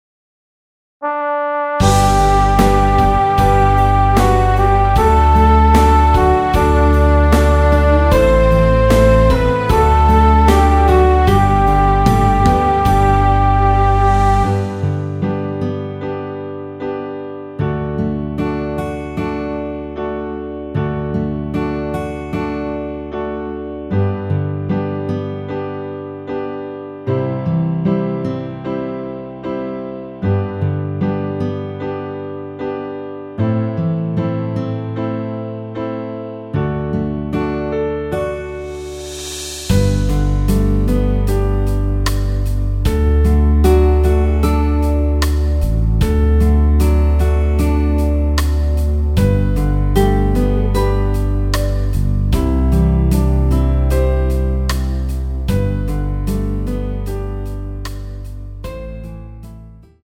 앞부분30초, 뒷부분30초씩 편집해서 올려 드리고 있습니다.
중간에 음이 끈어지고 다시 나오는 이유는
위처럼 미리듣기를 만들어서 그렇습니다.